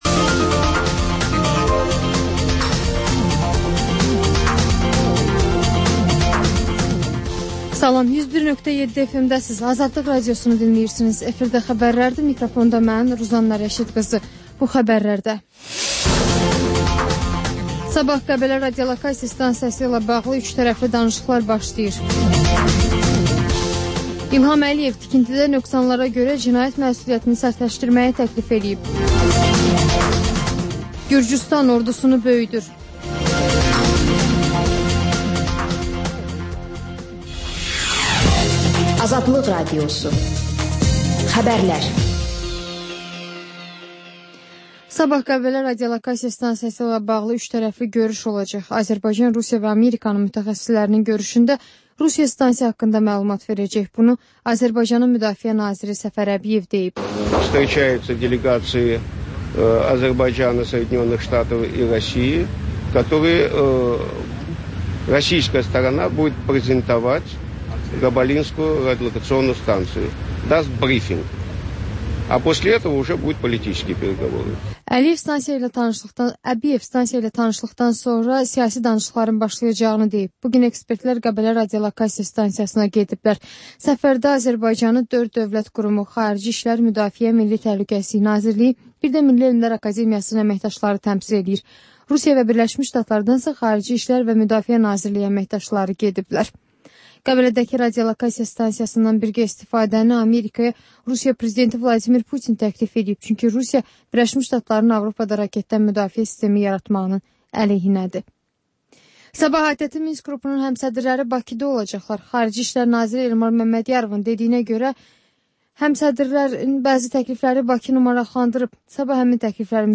Xəbərlər, müsahibələr, hadisələrin müzakirəsi, təhlillər, sonda isə XÜSUSİ REPORTAJ rubrikası: Ölkənin ictimai-siyasi həyatına dair müxbir araşdırmaları